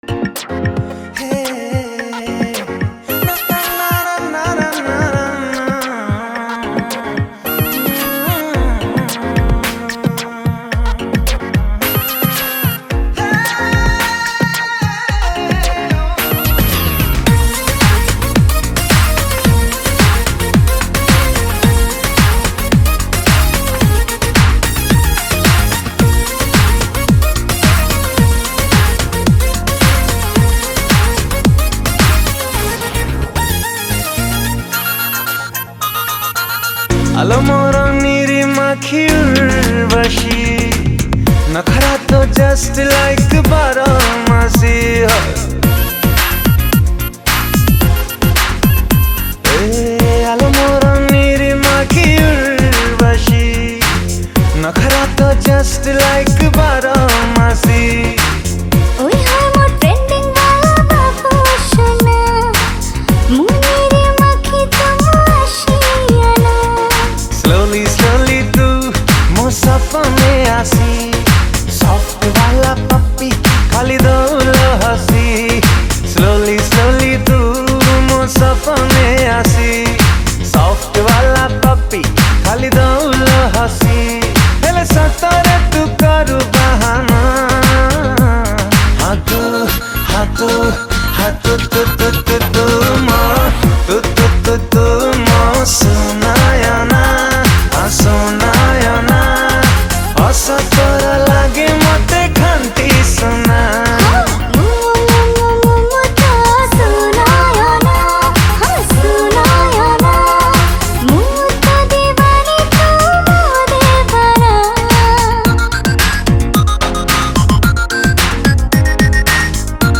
Song Type :Romantic Song